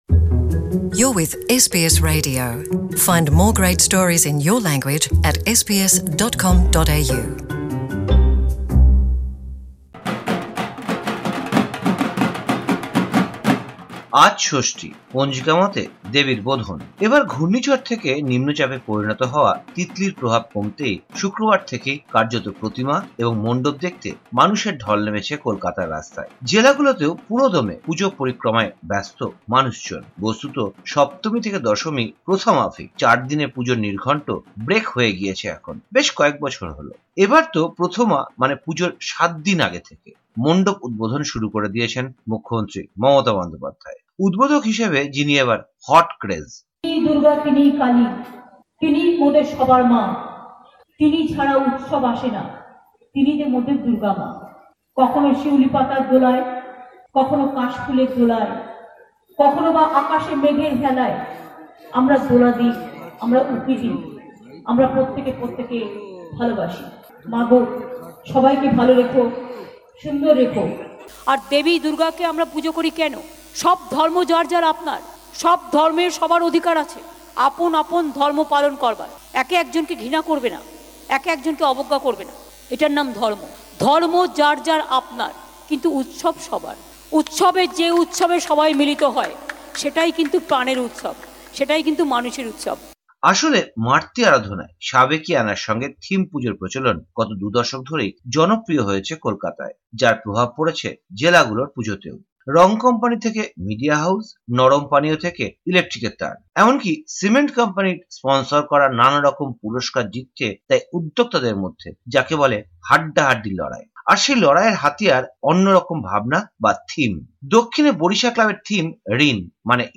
কলকাতায় দুর্গা পূজার প্রস্তুতি নিয়ে একটি বিশেষ প্রতিবেদন।